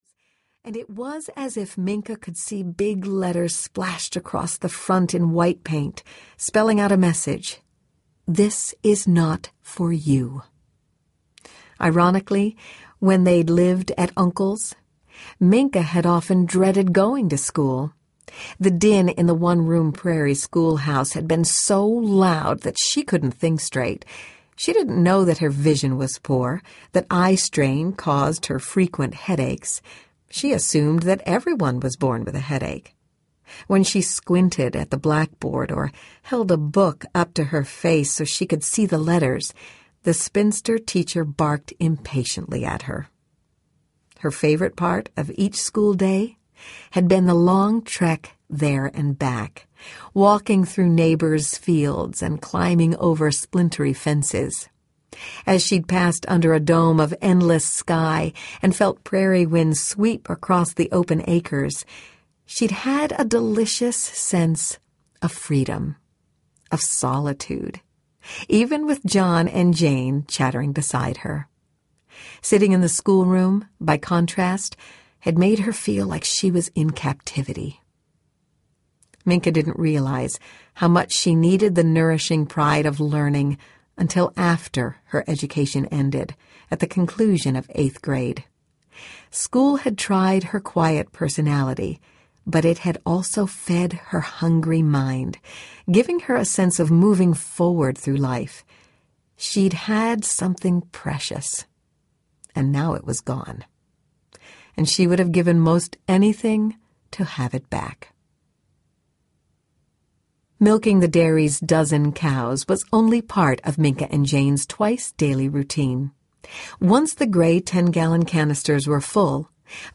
The Waiting (Cathy LaGrow) Audiobook
Narrator
10 Hrs. – Unabridged